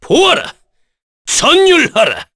Kain-Vox_Skill2_kr_b.wav